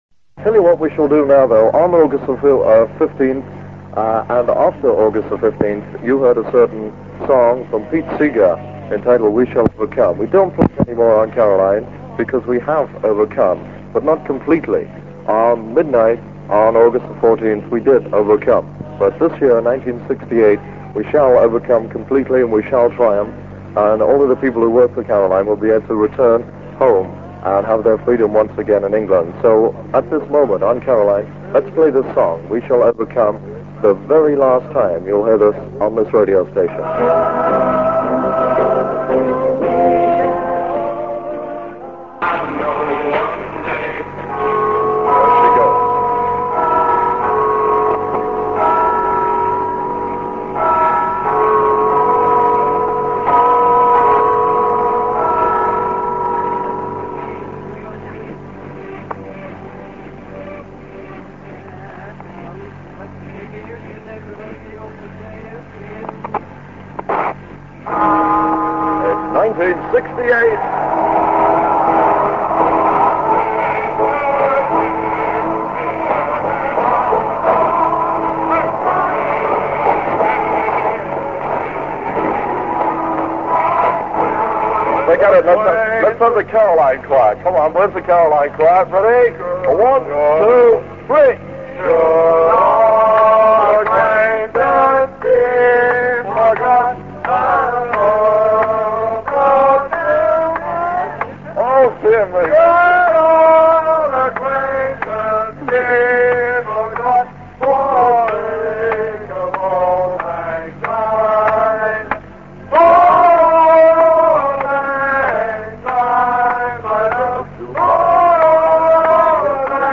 with the chimes of Big Ben, courtesy of a radio tuned to the BBC, and some particularly dreadful singing. Radio Caroline South, New Year's Eve 1967 (duration 4 minute 16 seconds)